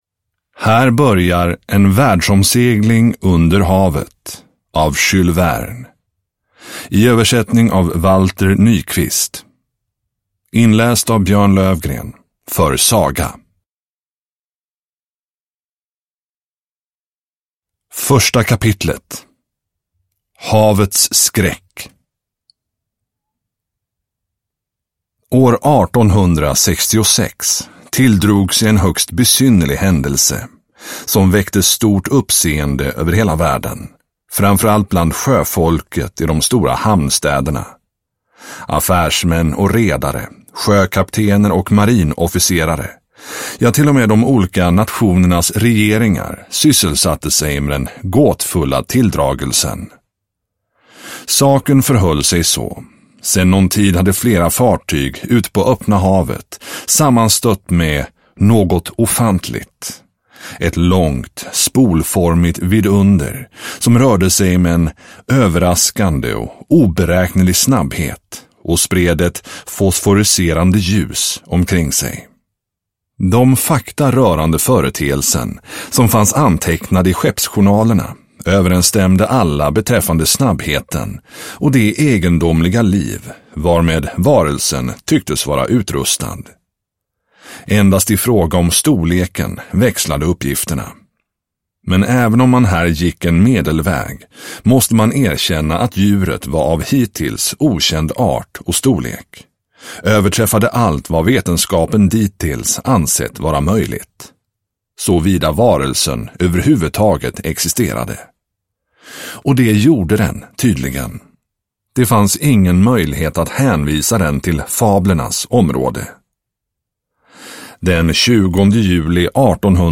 En världsomsegling under havet – Ljudbok – Laddas ner